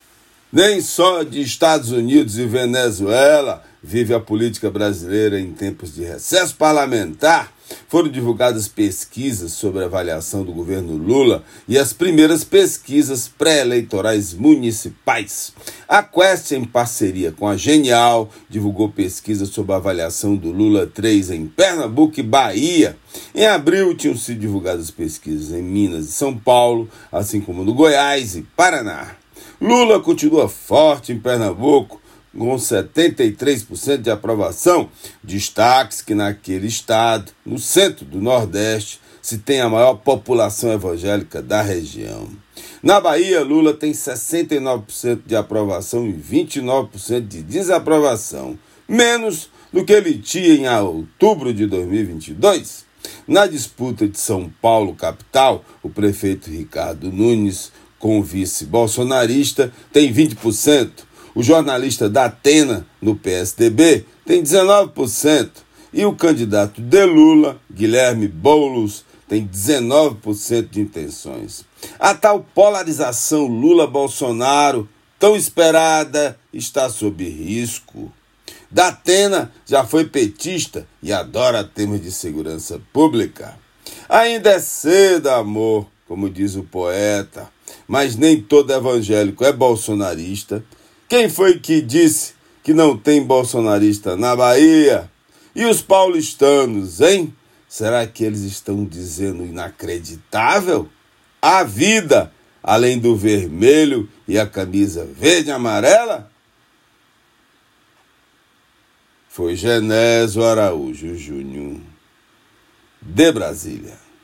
Comentário desta quinta-feira
direto de Brasília.